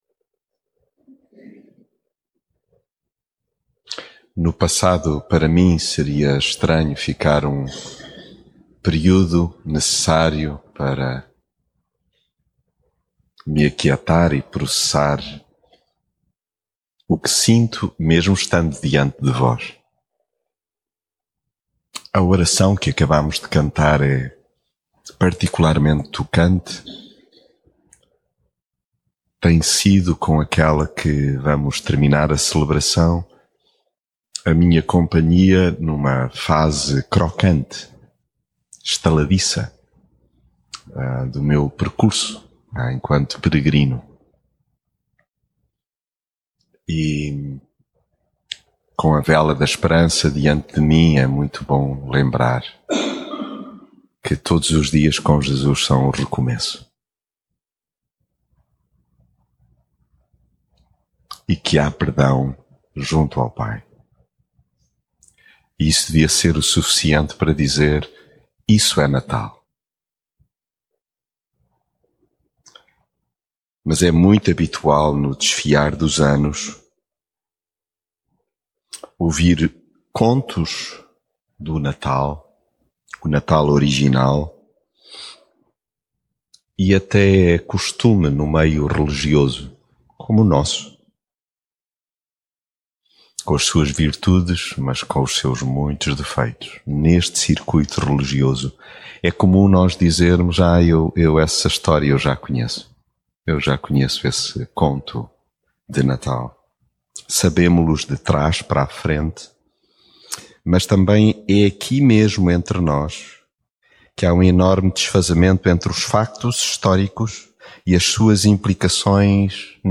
mensagem bíblica É habitual no desfiar dos anos ouvir os contos do Natal original, pelo que é comum, pelo menos no meio religioso, sabê-los de...